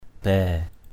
/bɛ:/